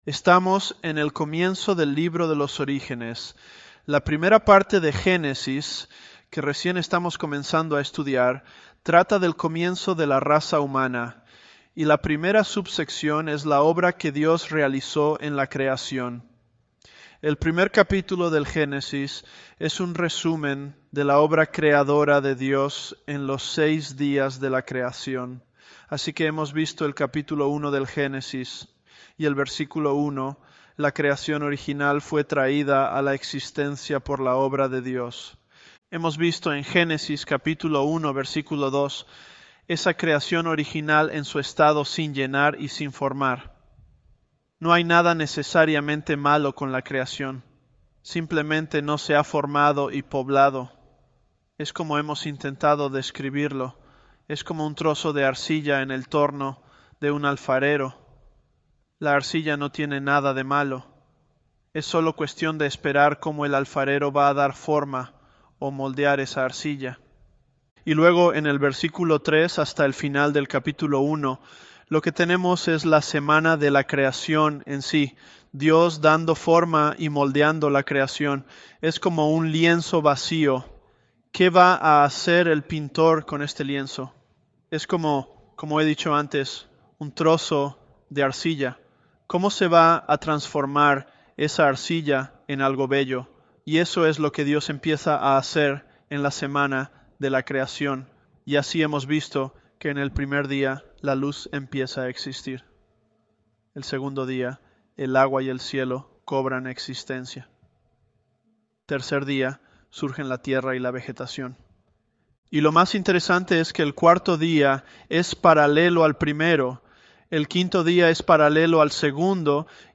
Sermons
ElevenLabs_Genesis-Spanish006.mp3